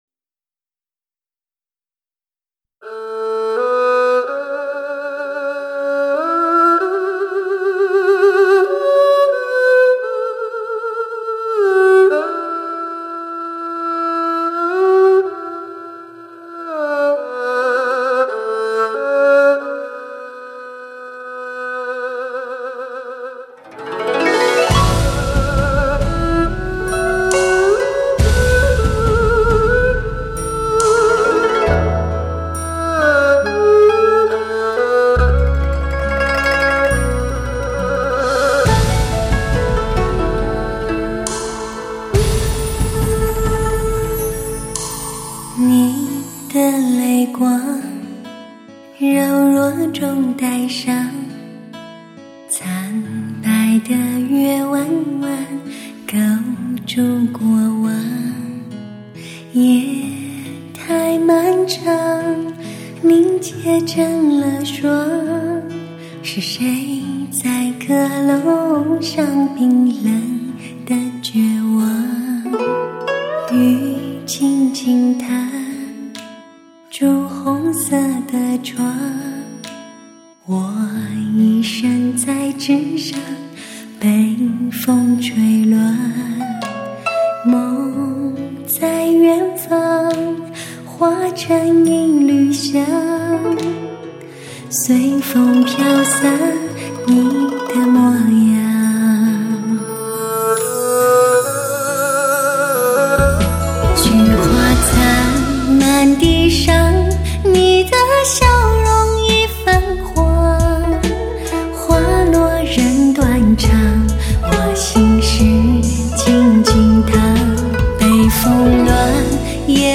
史上人声最甜美、感情最丰富的女声。